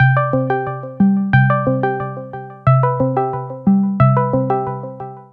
Звуки Discord
Звонок раздается